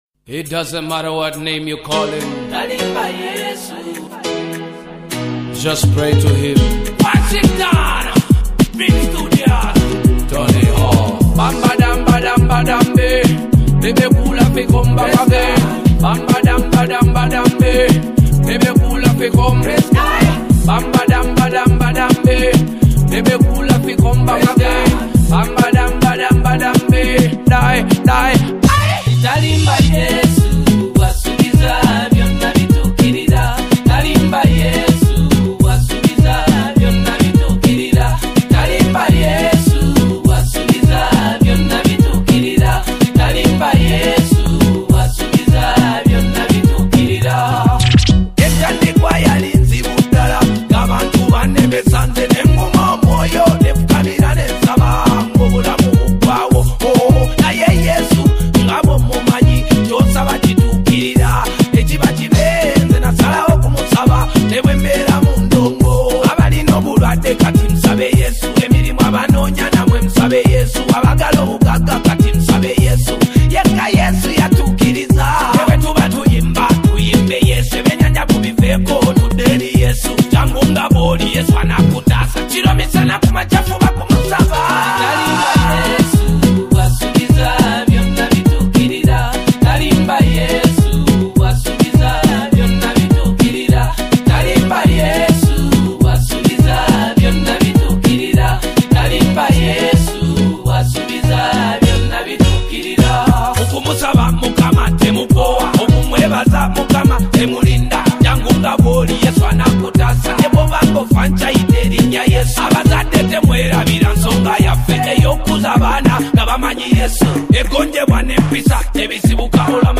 gospel song
Through calm rhythms and strong words